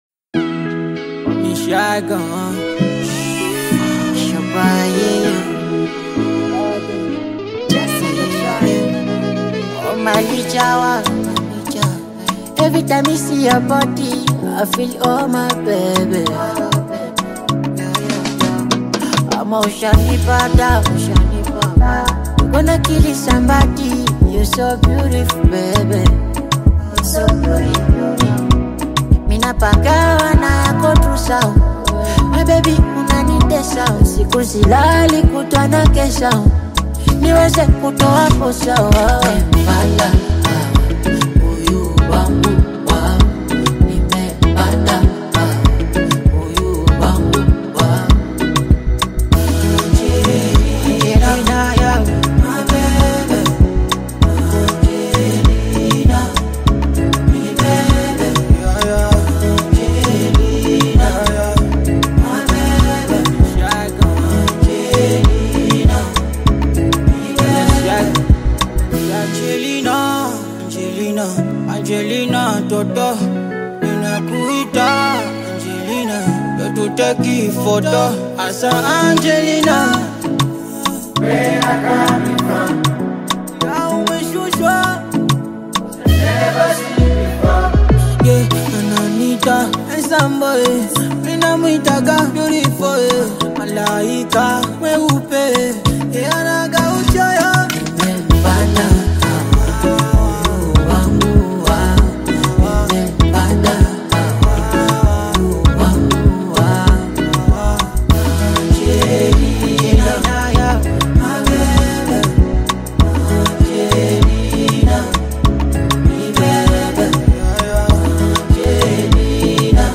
soulful, sultry vocals weave a tapestry of raw emotion